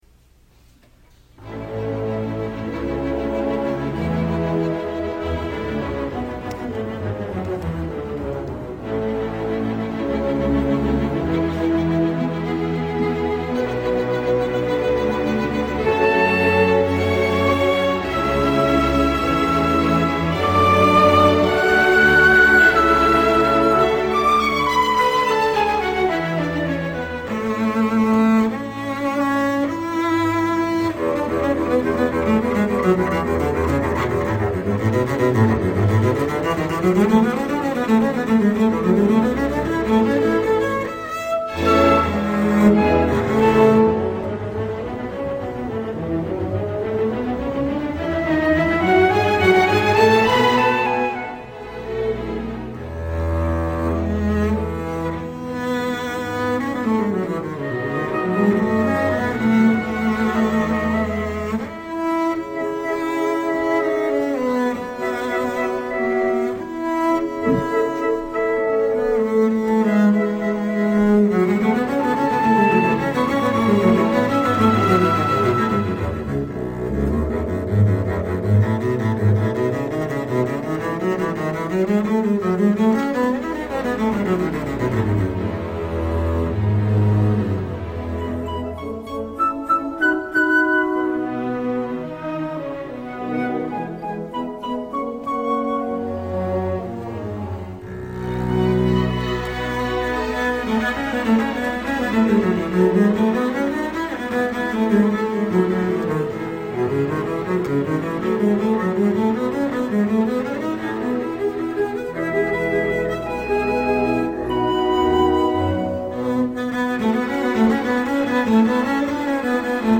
Solo concerto
Double Bass Concert